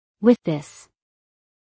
with-this-us-female.mp3